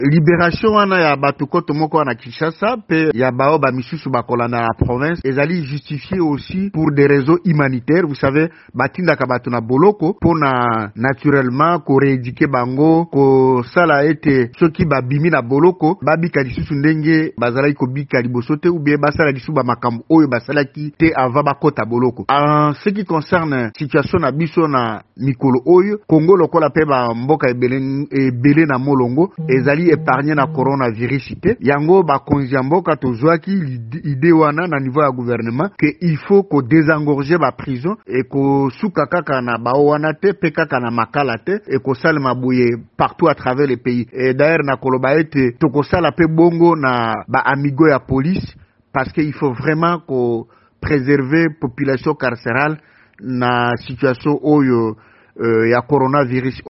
Pene na 1.200 babimisami na boloko ya Makala na Kinshasa (CPRK) mpo na bobangi bokoti mpe bopanazani bwa COVID-19. VOA Lingala etunaki ministre ya Makoki ma bomoto, André Lite Asebea.